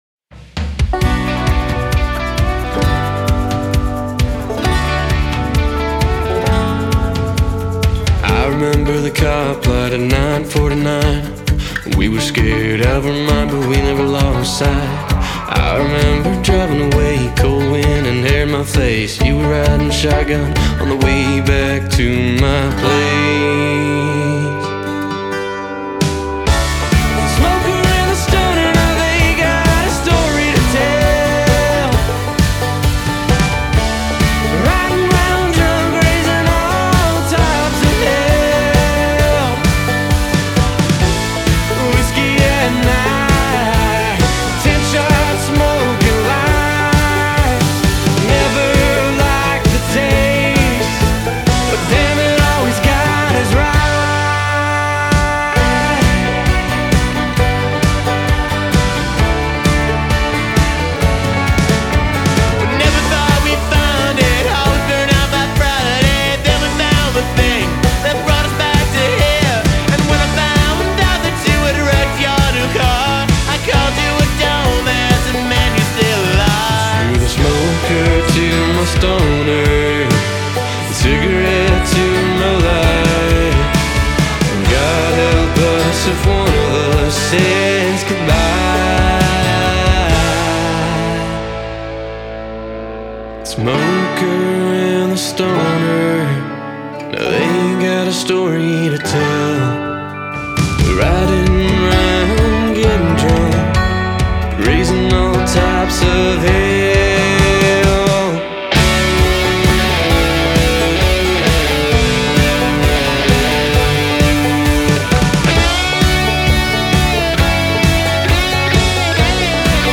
Vor allem die melodischen Gitarren.